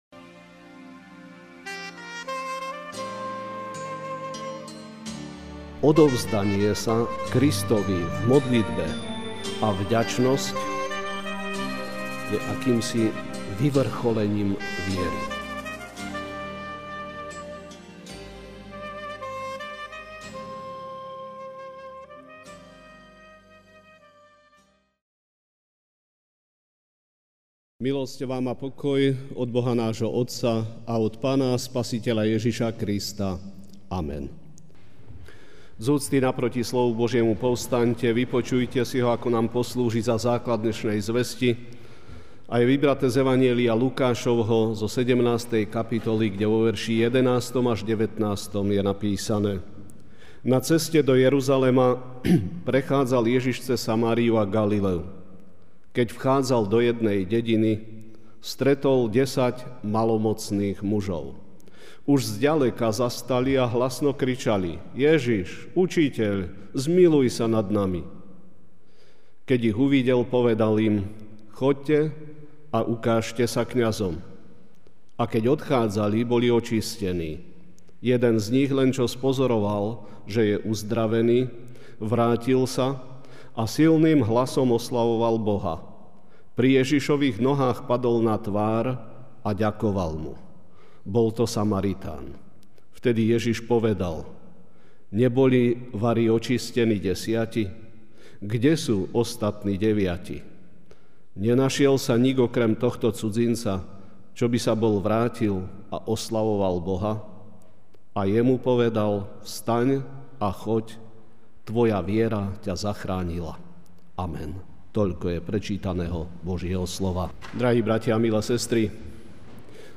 kázeň-17.9-2.mp3